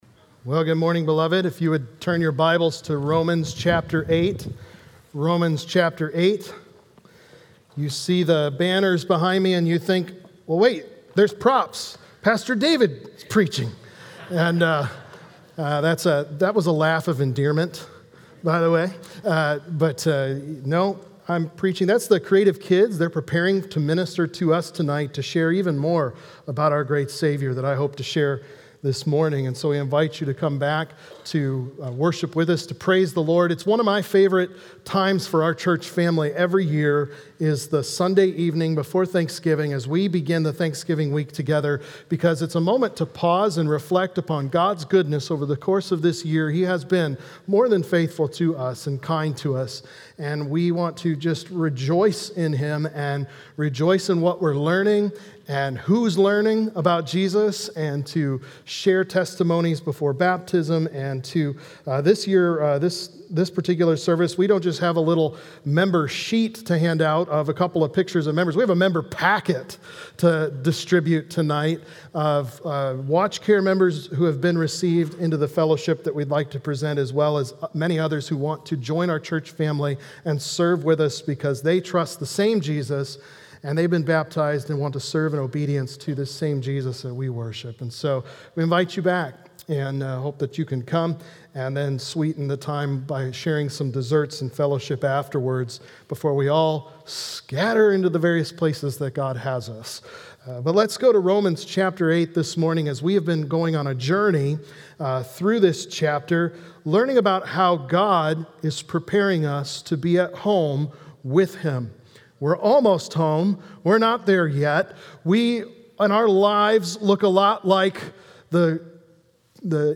| Baptist Church in Jamestown, Ohio, dedicated to a spirit of unity, prayer, and spiritual growth